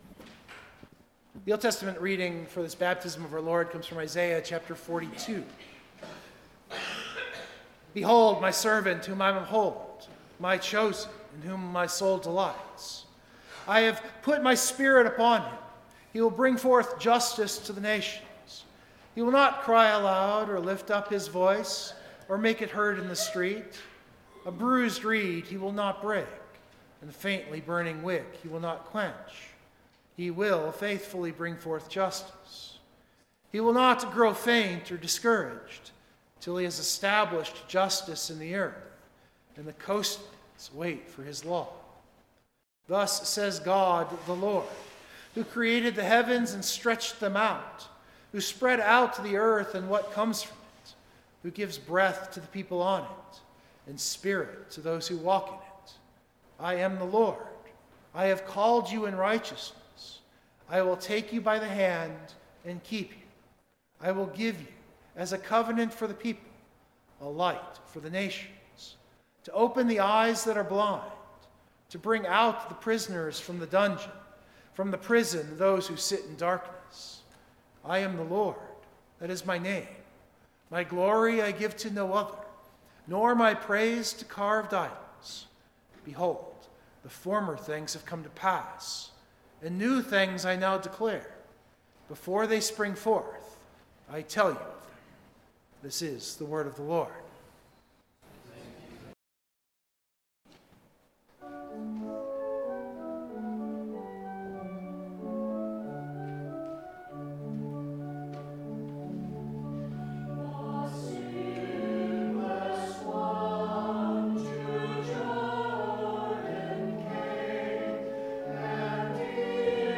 Scripture Text: John 8:31-36 Full Text of Sermon